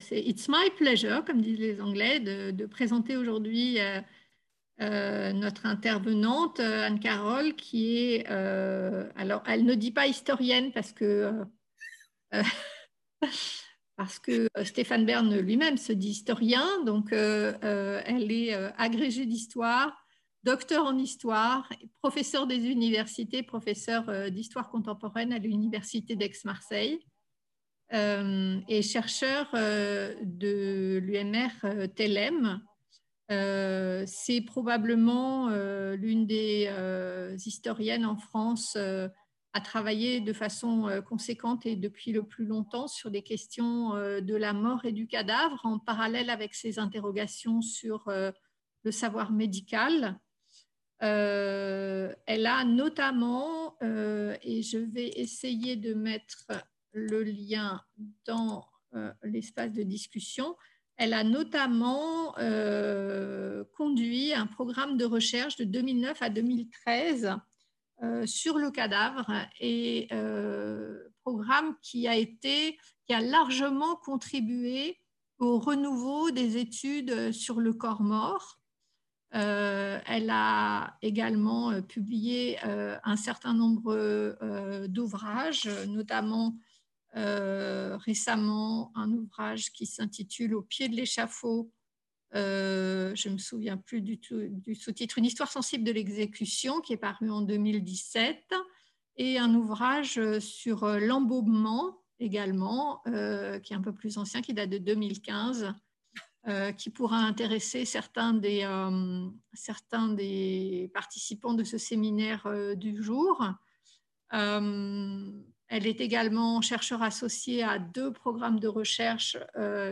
Enregistrement sur Zoom.